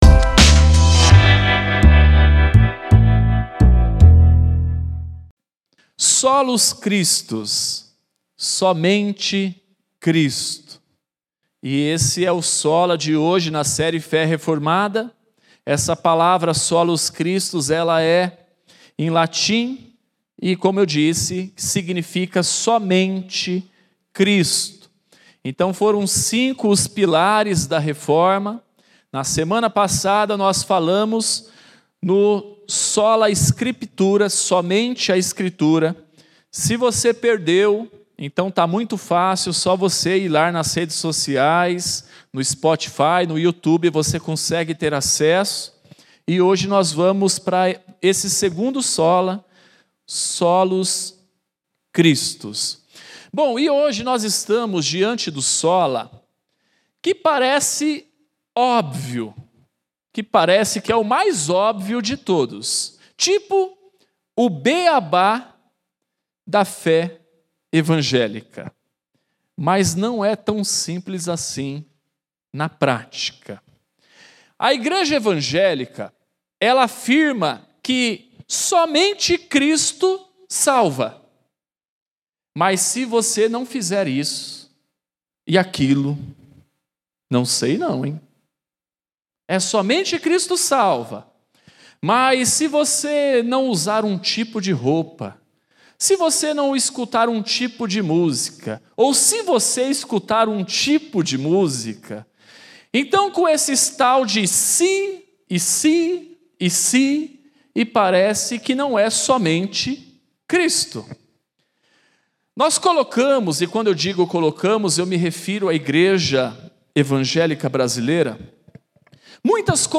Segunda mensagem da série FÉ REFORMADA ministrada